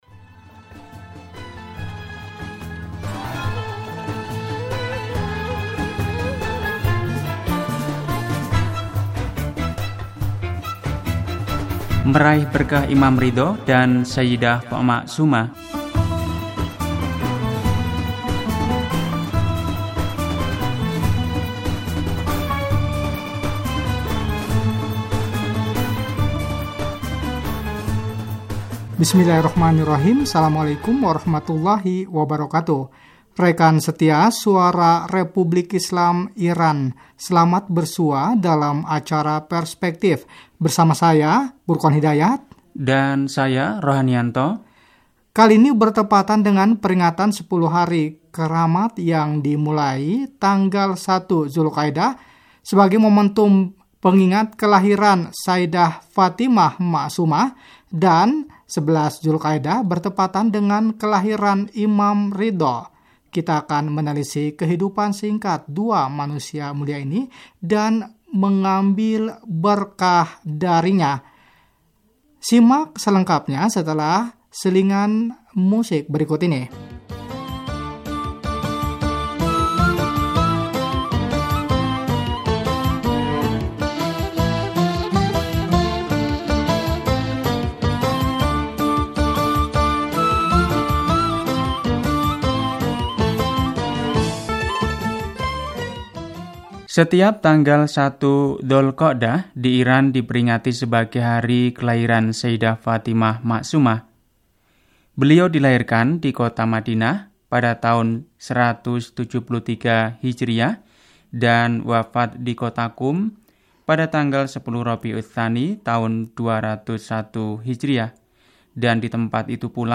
Wawancara 1 Salah satu berkah keberadaan Sayidah Fatimah, di Qom berdiri salah satu pusat pendidikan agama terbesar di dunia.